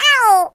Ouch.ogg